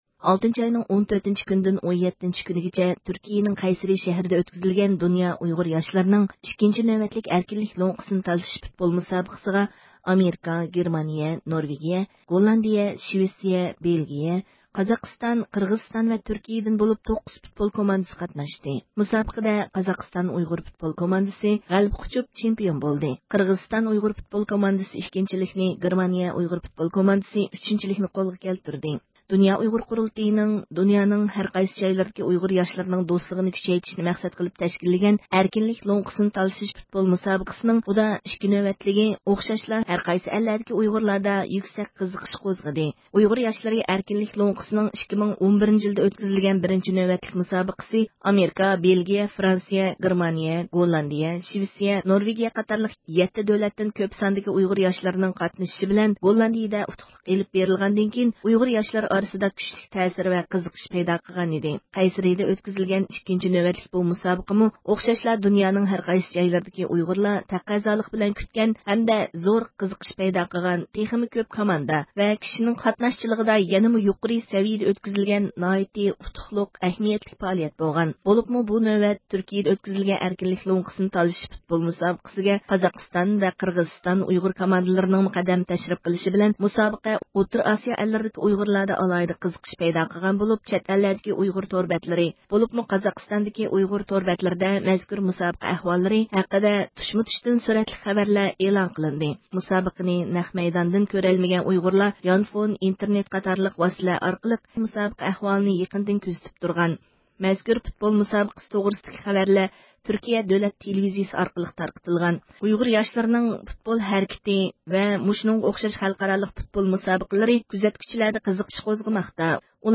جەنۋەدە مۇھىم يىغىنغا قاتنىشىۋاتقان ئۇيغۇر مىللىي ھەرىكىتى رەھبىرى رابىيە قادىر خانىم زىيارىتىمىزنى قوبۇل قىلىپ، ئىككىنچى نۆۋەتلىك ئەركىنلىك لوڭقىسىنى تالىشىش پۇتبول مۇسابىقە پائالىيەتلىرىنىڭ غەلىبىسىنى تەبرىكلىدى ۋە پۇتبول ھەرىكىتىنىڭ ئۇيغۇرلار ئارىسىدىكى تەسىرى ھەم ئۇنىڭ ئەھمىيىتىگە يۇقىرى باھا بېرىپ «ئۇيغۇرلاردىكى پۇتبول روھى-ئەركىنلىك روھى» دەپ بىلدۈردى ھەمدە بۇ پۇتبول مۇسابىقىسىگە دۇنيا ئۇيغۇر قۇرۇلتىيىنىڭ ئىزچىل ئەھمىيەت بېرىپ كېلىۋاتقانلىقىنى، مۇسابىقە ئەھۋالىدىن خەۋەردار بولۇپ تۇرغانلىقىنى ھەمدە بۇ مۇسابىقىدىن، ئۇيغۇر پەرزەنتلىرىدىكى پۇتبول ھەرىكىتىگە بولغان قىزغىنلىقتىن، بۇ خىلدىكى مۇسابىقىلەرنى ھەر قانداق قىيىنچىلىقلارنى يېڭىپ ئۈزۈلدۈرمەي ئۆتكۈزۈش كېرەكلىكىنى ئەسكەرتتى.